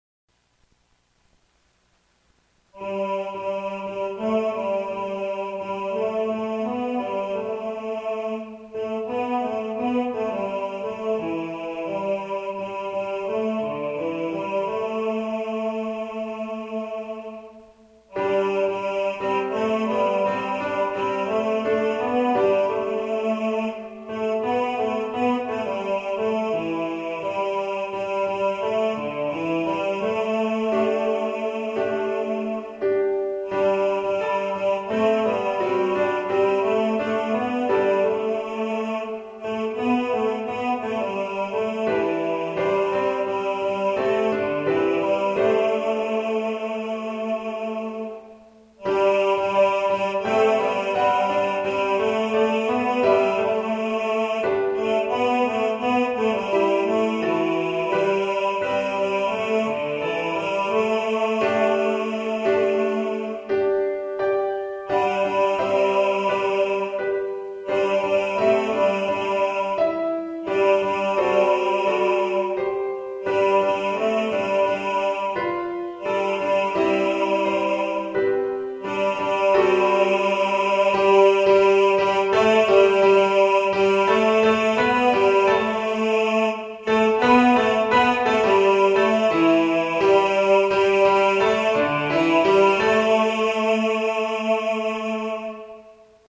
Vuelie-Bass.mp3